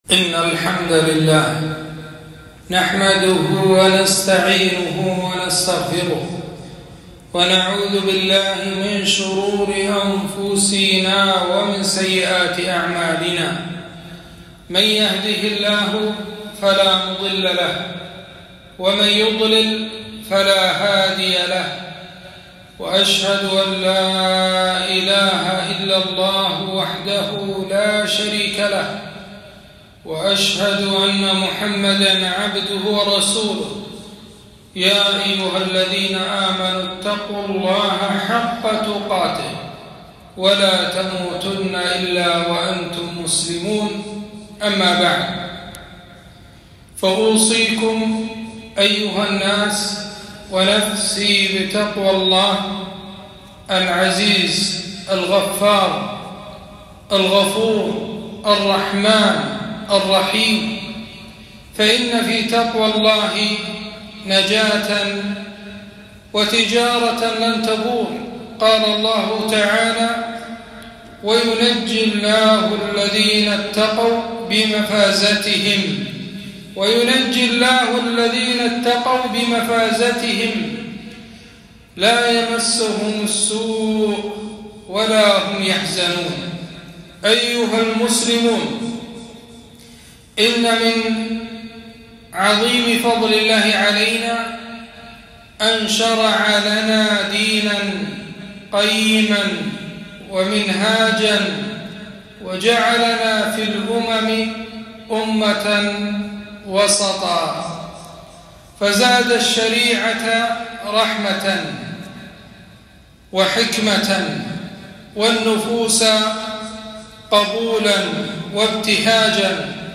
خطبة - ( ولا تسرفوا إنه لا يحب المسرفين )